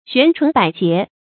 懸鶉百結 注音： ㄒㄨㄢˊ ㄔㄨㄣˊ ㄅㄞˇ ㄐㄧㄝ ˊ 讀音讀法： 意思解釋： 鵪鶉的羽毛又短又花，因以懸鶉比喻破爛的衣服。